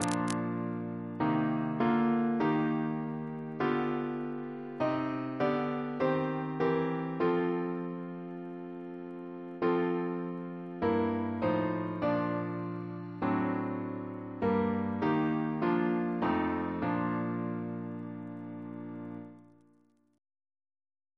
Double chant in B♭ Composer: W. H. Longhurst (1819-1904), Organist of Canterbury Cathedral Reference psalters: ACB: 80; H1982: S226